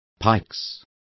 Also find out how pixides is pronounced correctly.